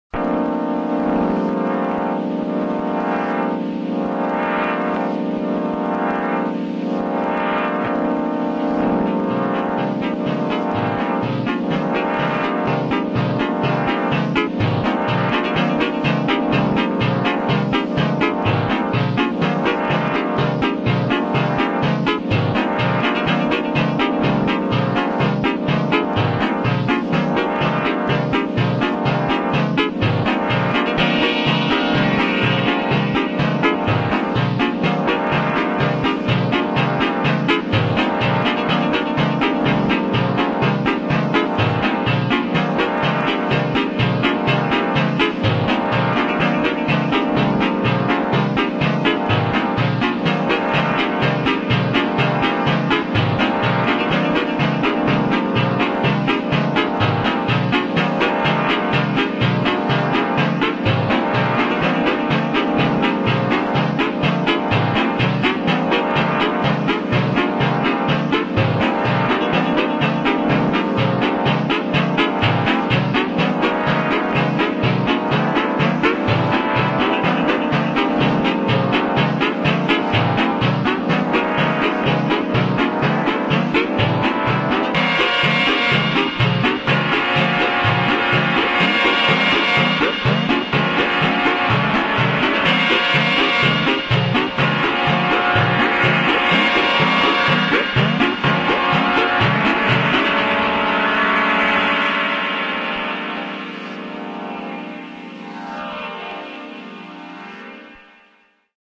The Hague neo-disco for the dark hours..
Disco